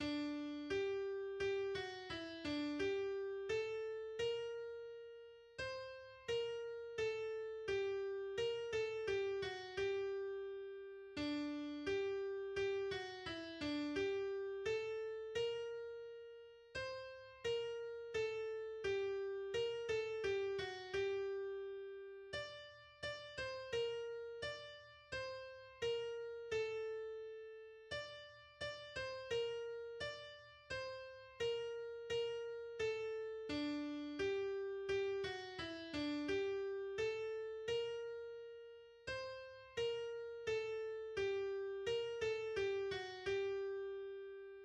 Arfon is a traditional Welsh hymn tune which exists with major and minor variants.[1][2]
Minor